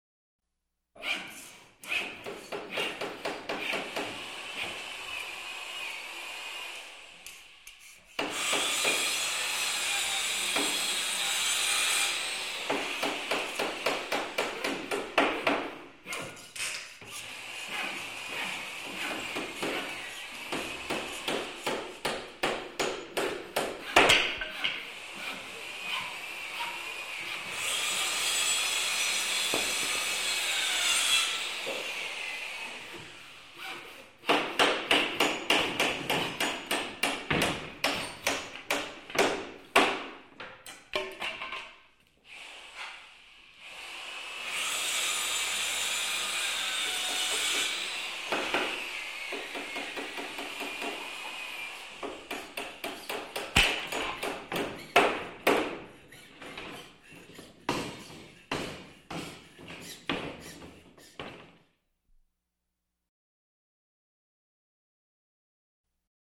звуки - ремонта
• Категория: Ремонтные работы
• Качество: Высокое